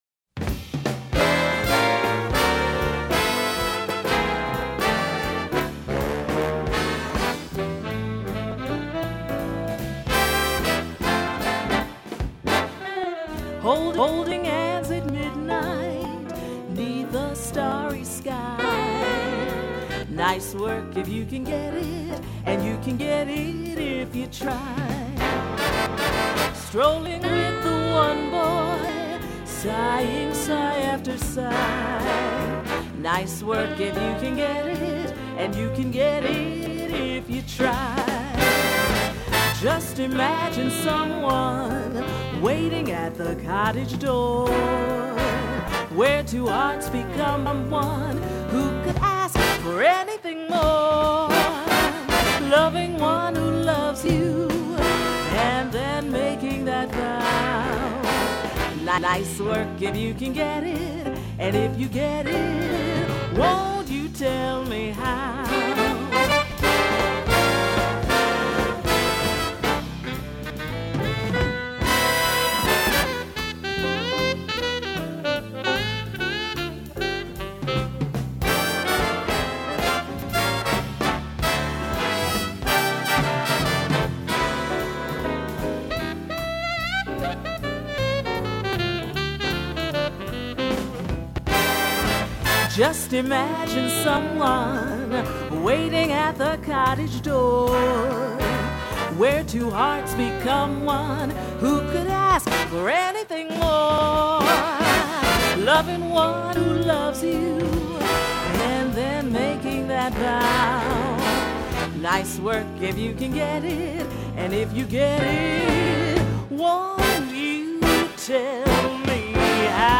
Voicing: Big Band with Vocal